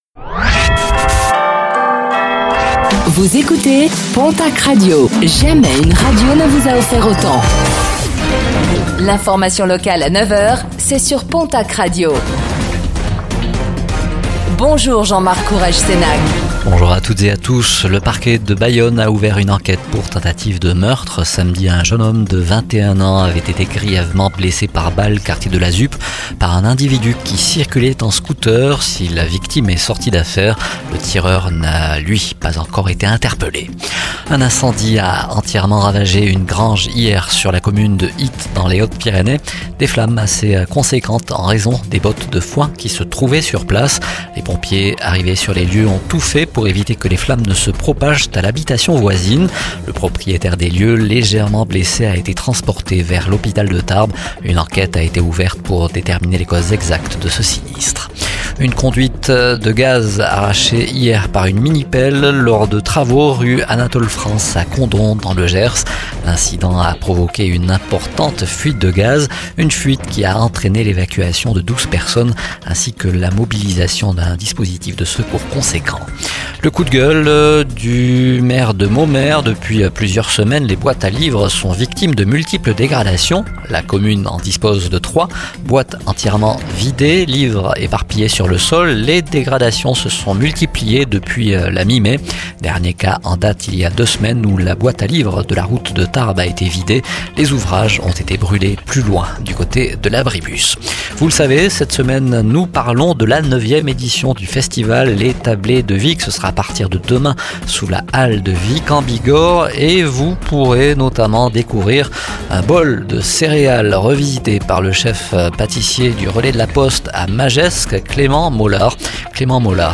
Infos | Jeudi 27 juin 2024